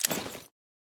armor-open-1.ogg